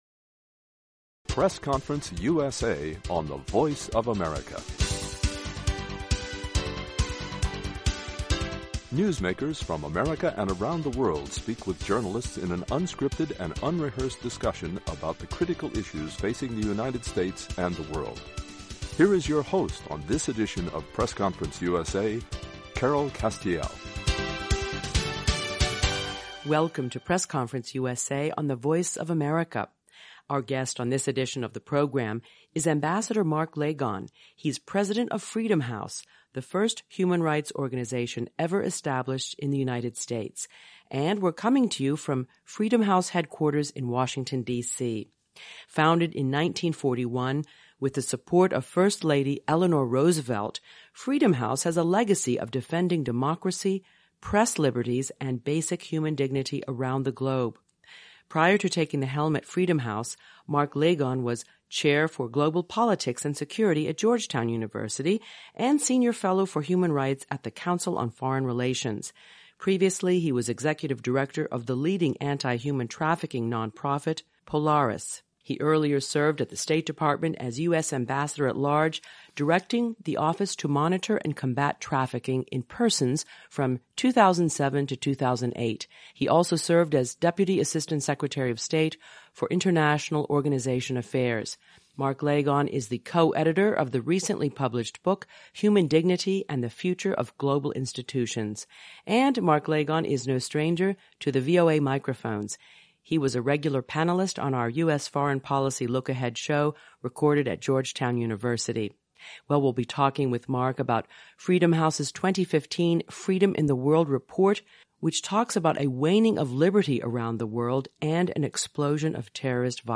MARK LAGON / FREEDOM HOUSE On this edition of Press Conference USA -- a conversation with Ambassador Mark Lagon, the new president of the oldest human rights organization in the United States: Freedom House.